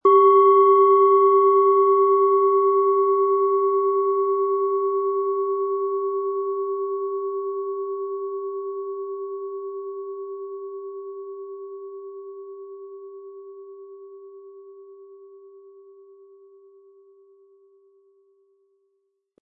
Tibetische Universal-Klangschale, Ø 12 cm, 180-260 Gramm, mit Klöppel
Wir haben diese Schale beim Aufnehmen angespielt und den subjektiven Eindruck, dass sie alle Körperregionen gleich stark anspricht.
Durch die traditionsreiche Fertigung hat die Schale vielmehr diesen kraftvollen Ton und das tiefe, innere Berühren der traditionellen Handarbeit.
MaterialBronze